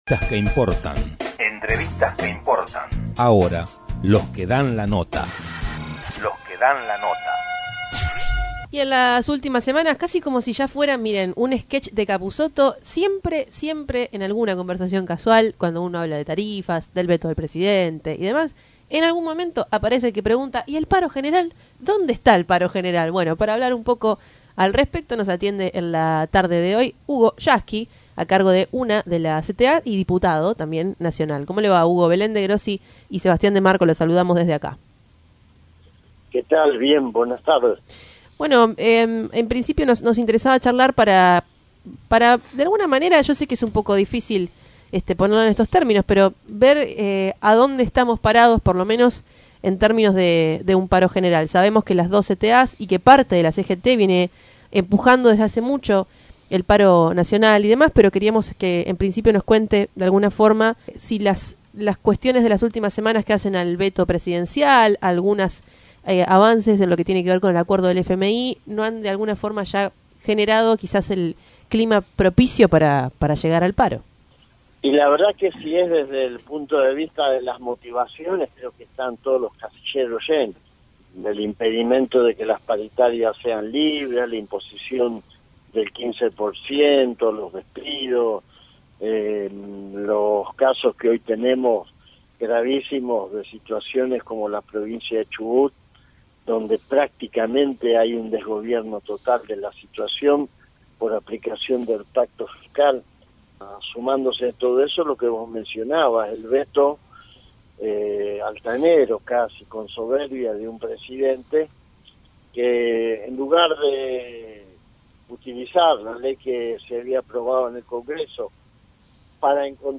Tren Urbano se comunicó con Hugo Yasky.